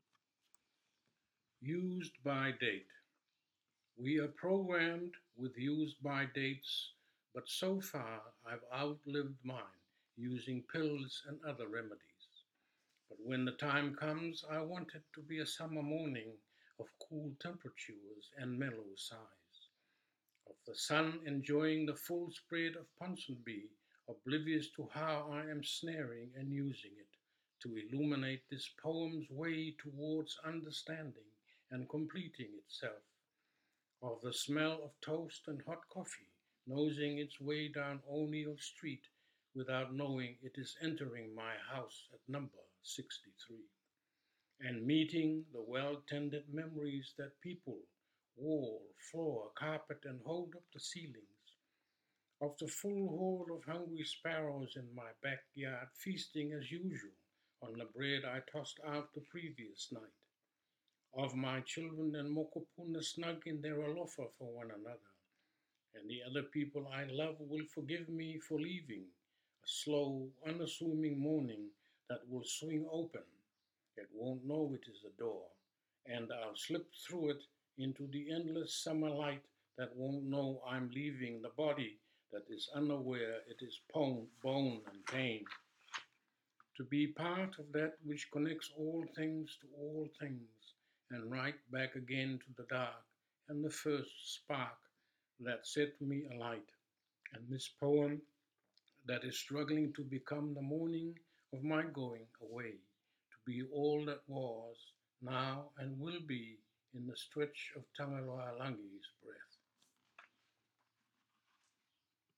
Albert Wendt reads ‘Used-by Date’